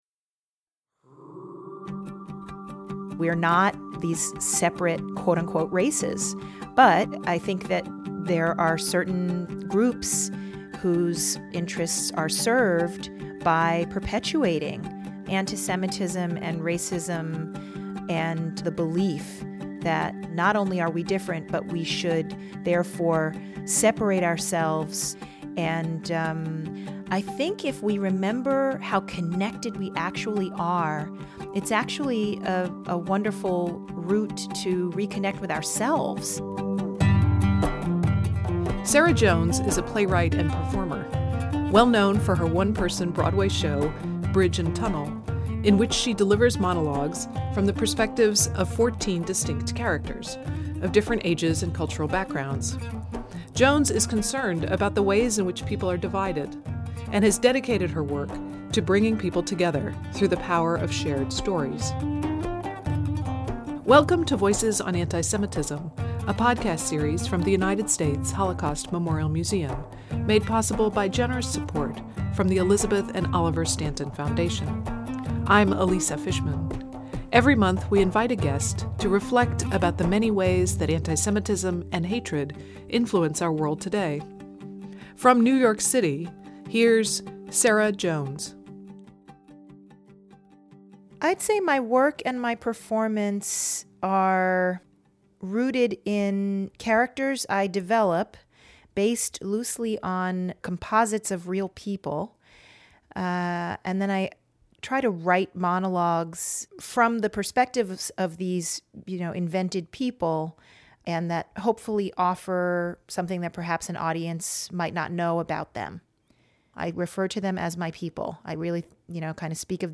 Sarah Jones playwright and performer